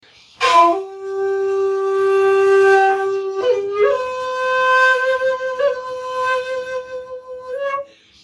Shakuhachi 62